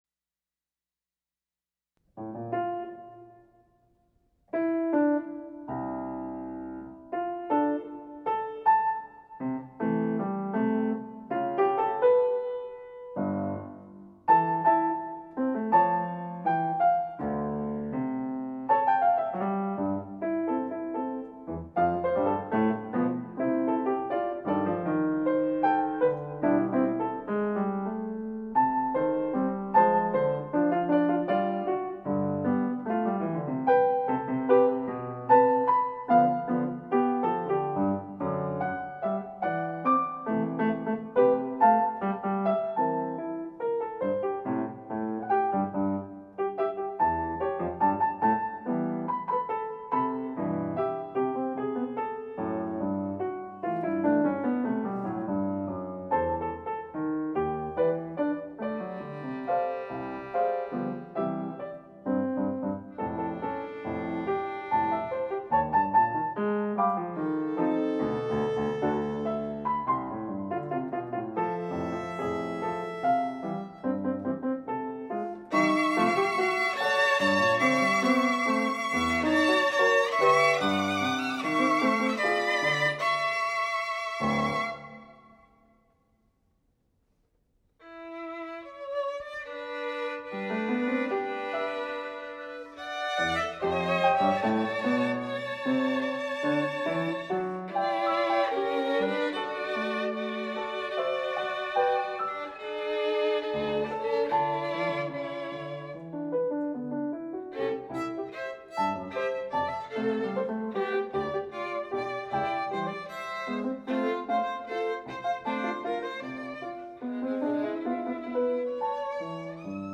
In this work the piano is made to represent the shoppers. The piano plays alone at first, as directionless as they are themselves. After quietly sounding each string, the violin interrupts with a loud melody, startling the customers. A medley of classical favorites ensues. The piano talks softly in the background, then joins in the music, even singing along. The mood of the entire mall becomes calm, then rises in emotion, dissolving into a happy frenzy.
So the violin, representing more than a violin, has the last word, a quiet note of hope placed at the end.